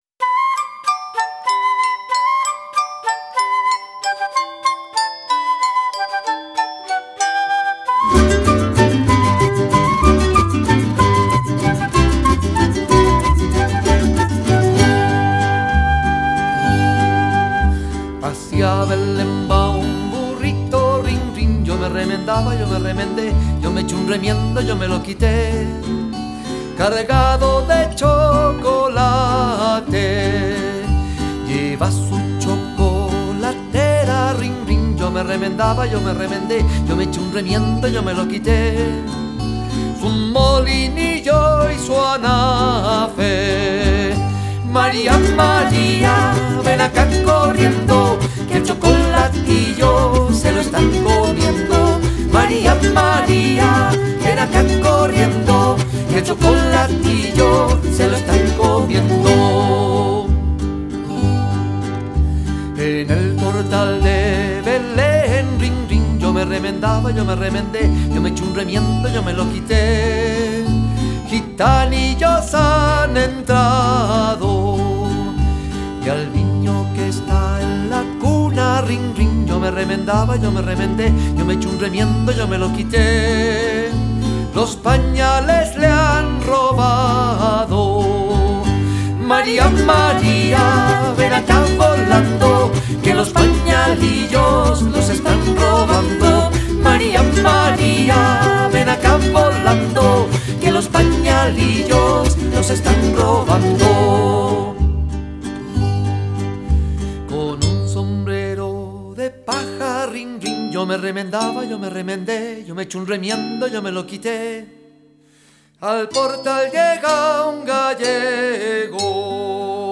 Canto
Música tradicional